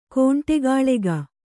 ♪ kōṇṭegāḷega